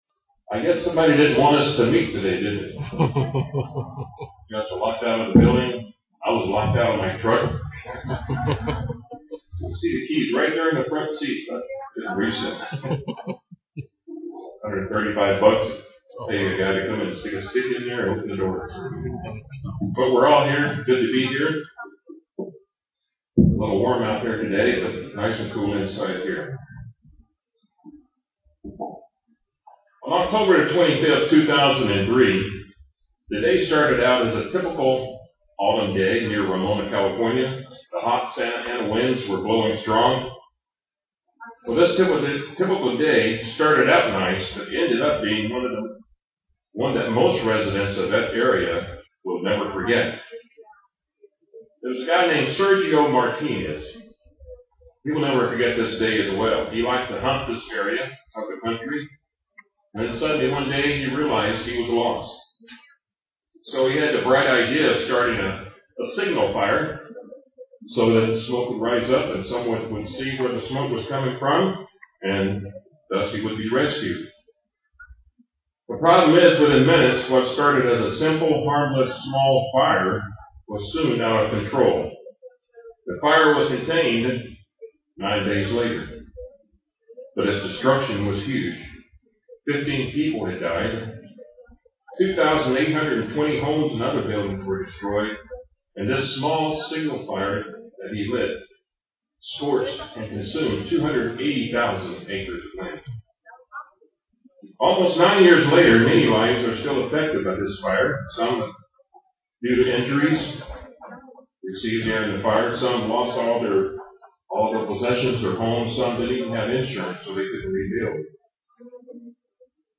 We need to learn how to tame our unruly tongues. *note: sound improves @ 9:46.
Given in Ft. Lauderdale, FL
UCG Sermon Studying the bible?